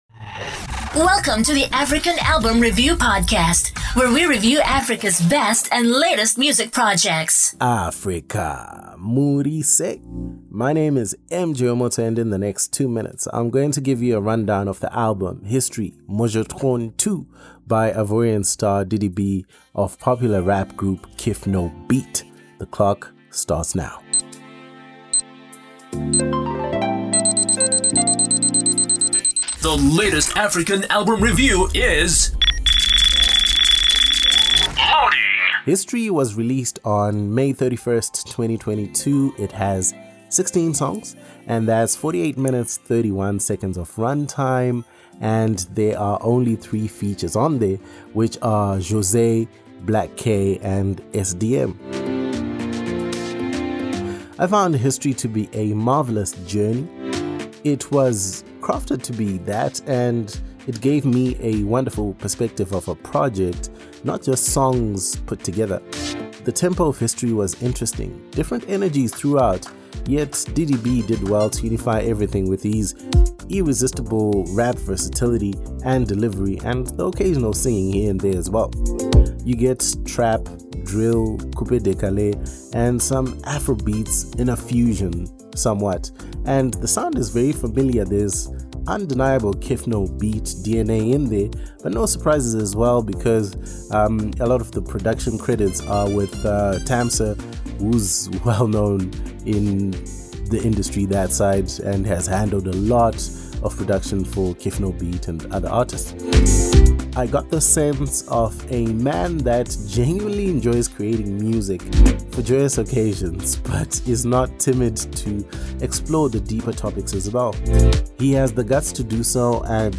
Music commentary and analysis on African albums